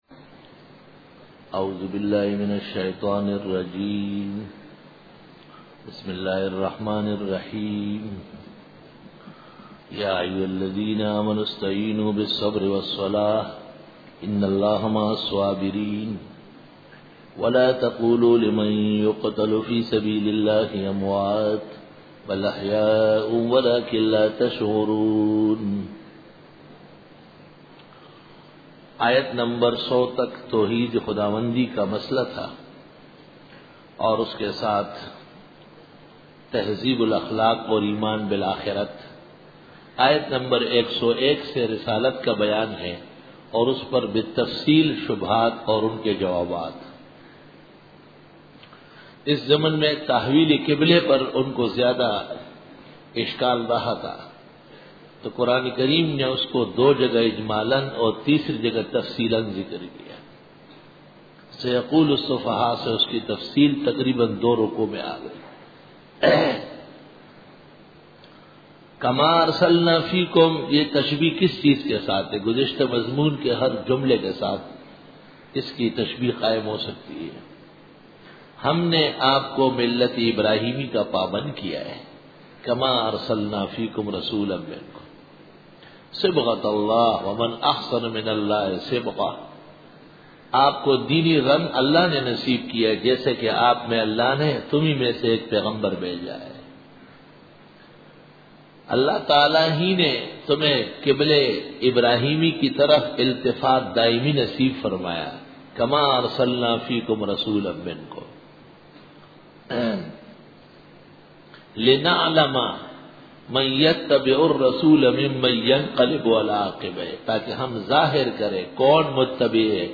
Dora-e-Tafseer 2004